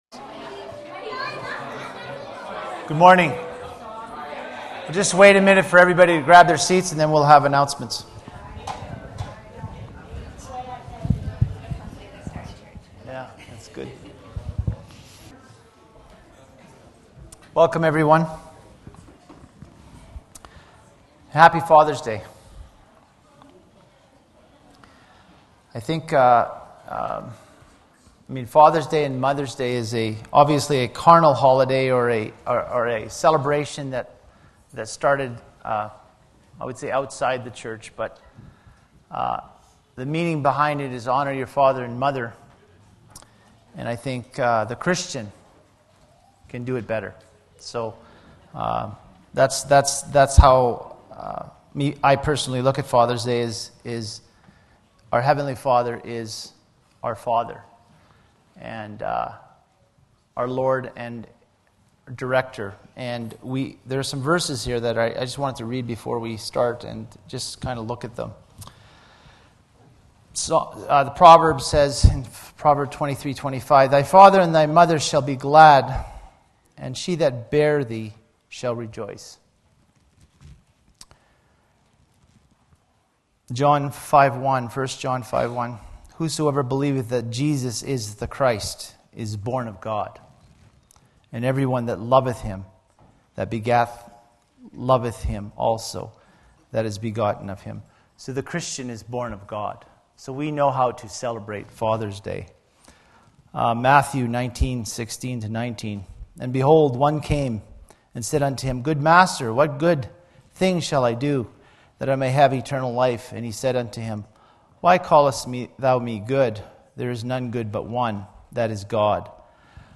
Sylvan Lake Apostolic Lutheran Church